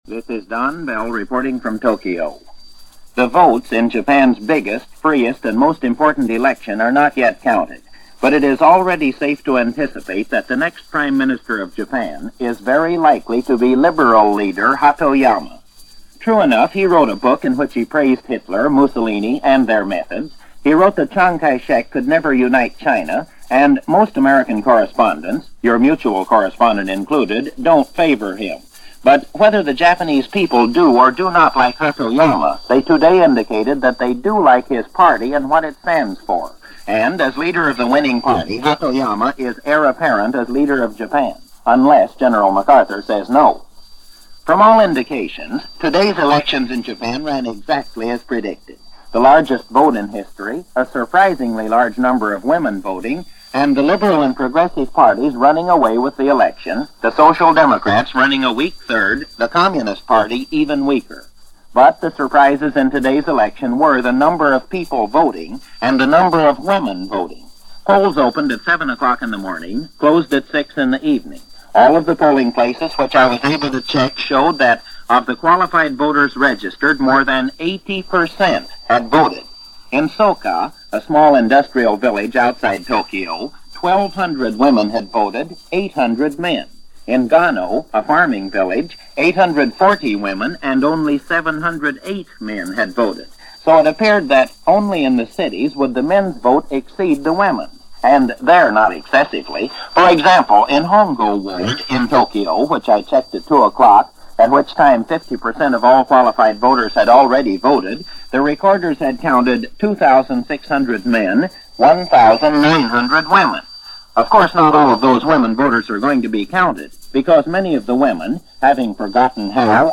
News-Reports-Shortwave-equalized-April-9-1946.mp3